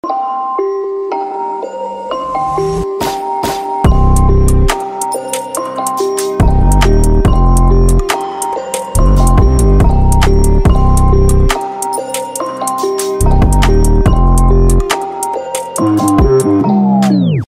Marimba Remix ,Uncategorized